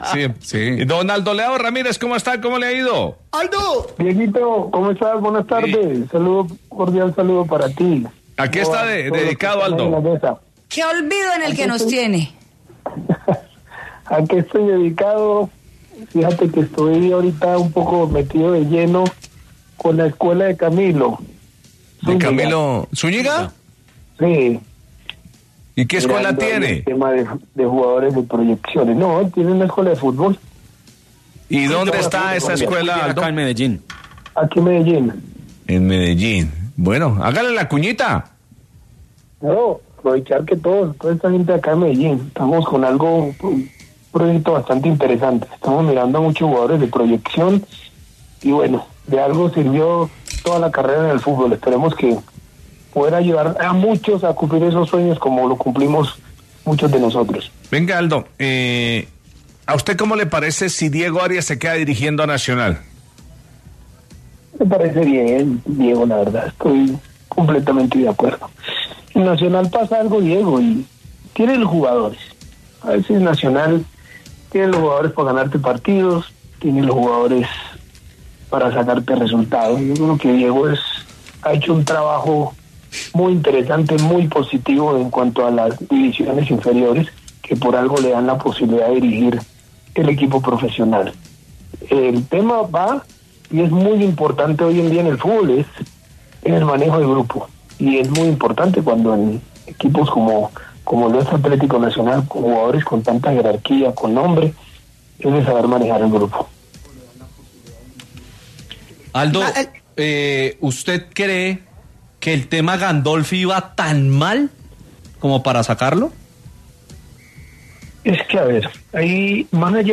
En diálogo con El VBar Caracol, Aldeo Leao, quien manifestó estar muy cercano al proyecto de la escuela de fútbol que tiene Camilo Zuñiga, aseguró que debe ser Arias el que quede como el técnico del cuadro Verdolaga.